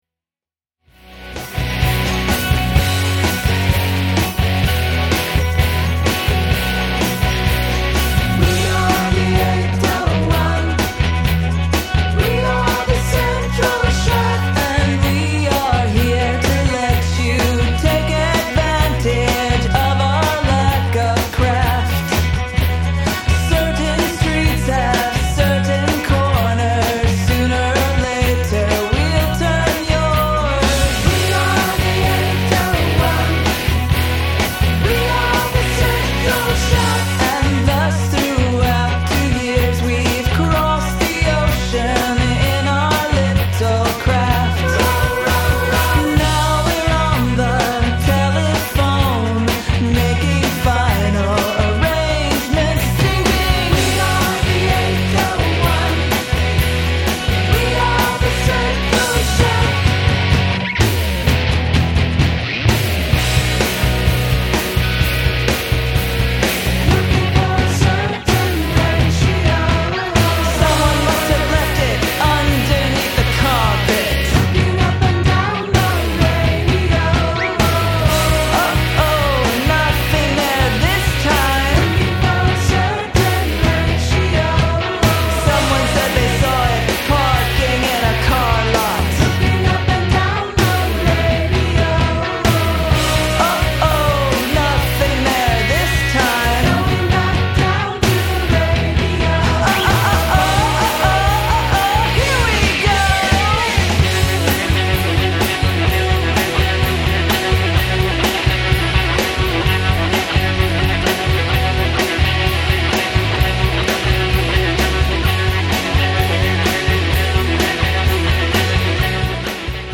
vocals, handclaps
There are no keyboards on this recording